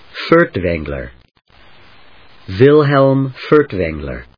アクセント・音節Furt・wäng・ler 発音記号・読み方/fˈʊɚtveŋlɚfˈʊətveŋlə/, Wilhelm /vílhelm/発音を聞く